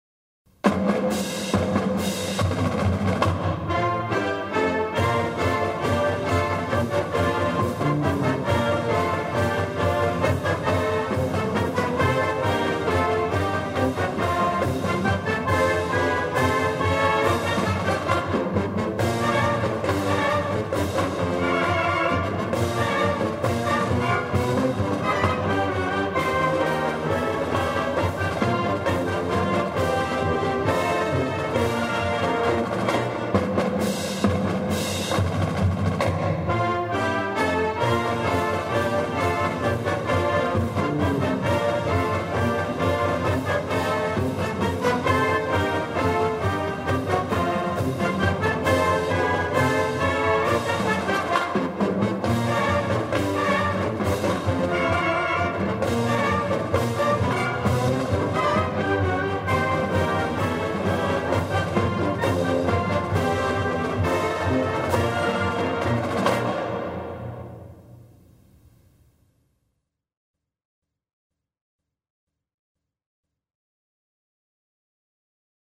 LHS Fight Song.mp3